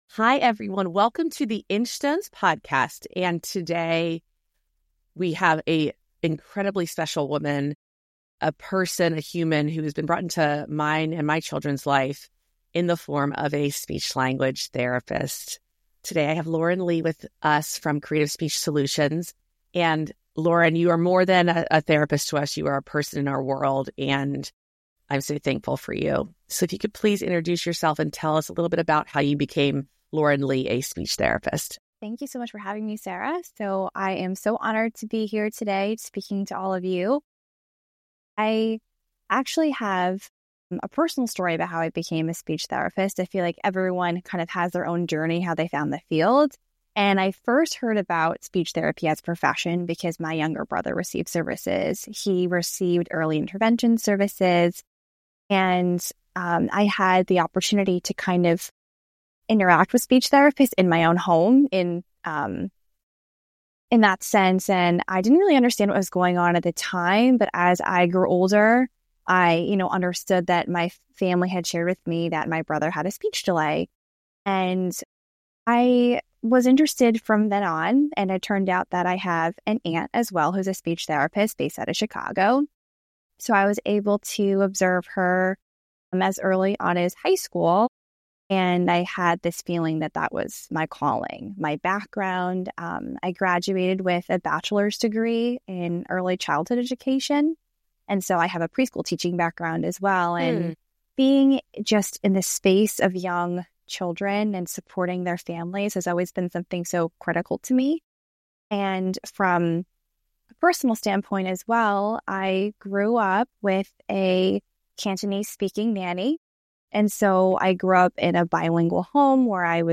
Whether you're navigating speech challenges or simply want to better understand how we all connect, this conversation is a must-listen.